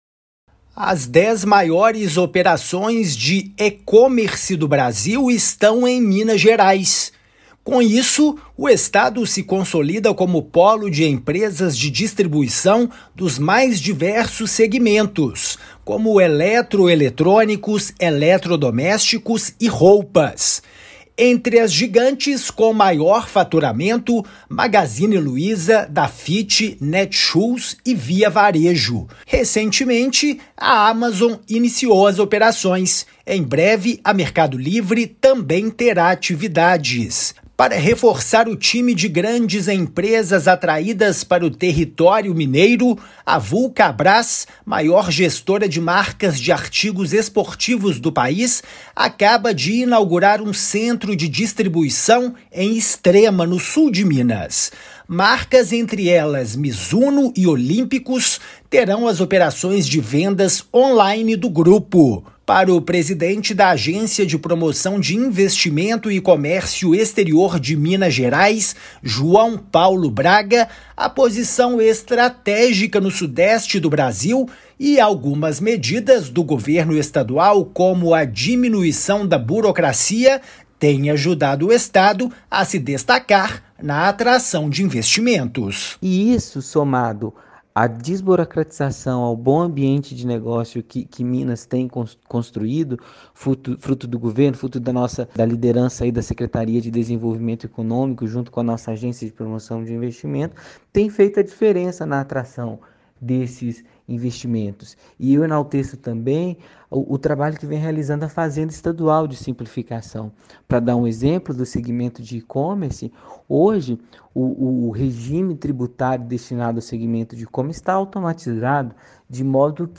Estado se consolida como polo de empresas de distribuição dos mais diversos segmentos como eletroeletrônicos, eletrodomésticos e roupas. Ouça a matéria de rádio.